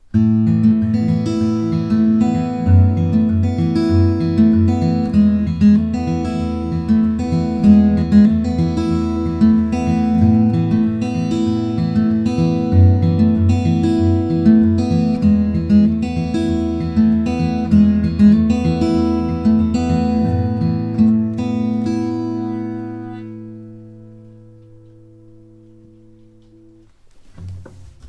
He made under fixed conditions samples of each guitar.
Picking 2
The warmer sound is from the 12 fret Dreadnought.